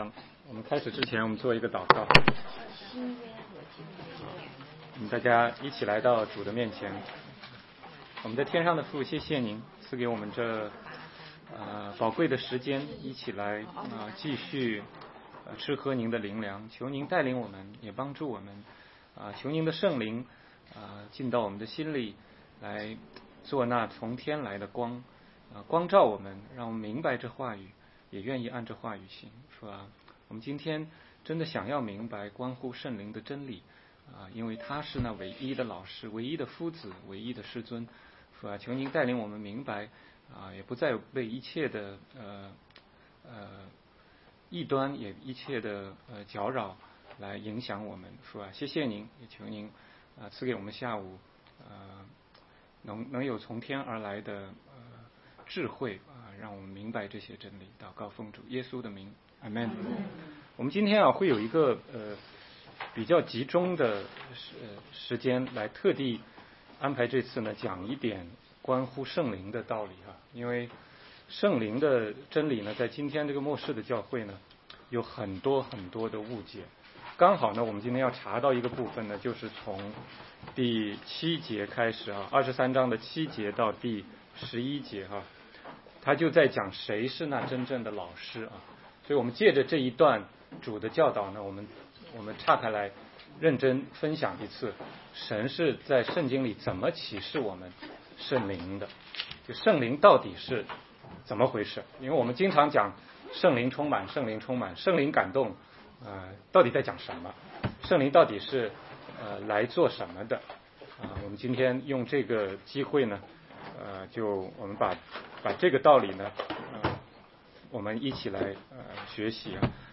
16街讲道录音 - 圣灵的工作